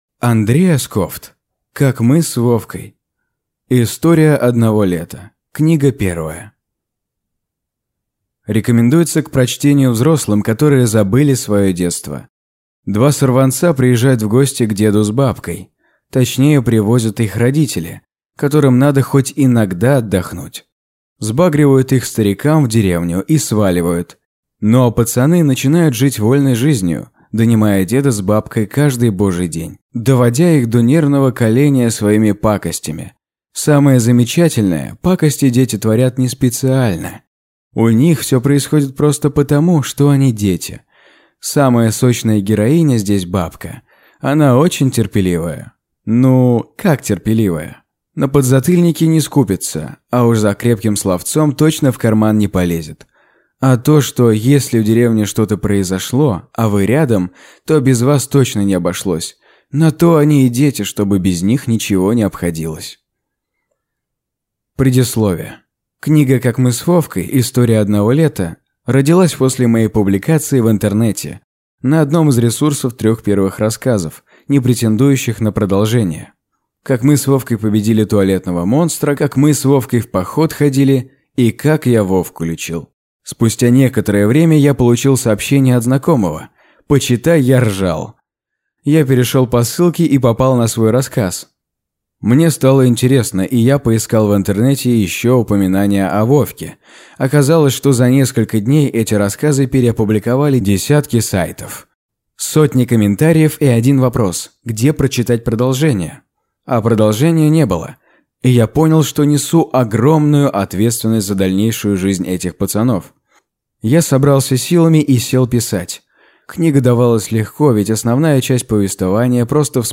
Аудиокнига Как мы с Вовкой.